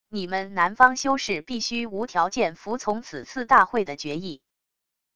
你们南方修士必须无条件服从此次大会的决议wav音频生成系统WAV Audio Player